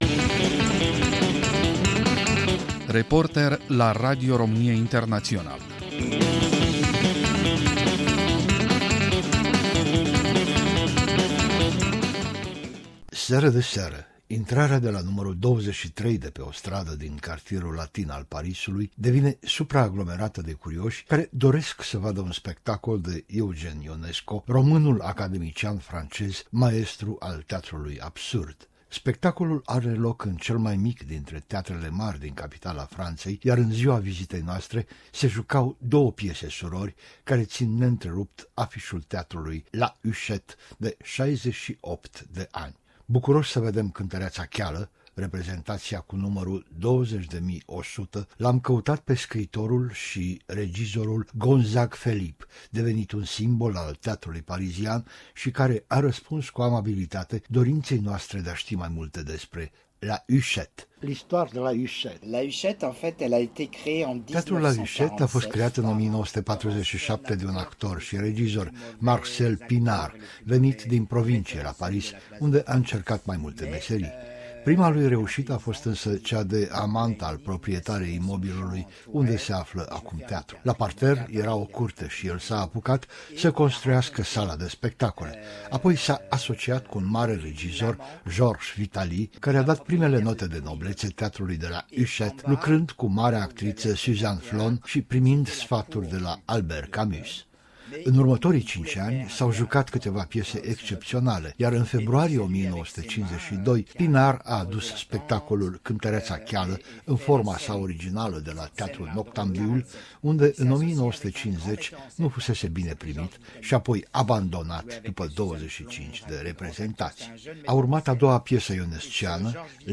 Am asistat la sediul teatrului din Cartierul Latin la reprezentația ”Cântăreața Cheală” cu numărul 20.100 și am dialogat cu dramaturgul